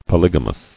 [po·lyg·a·mous]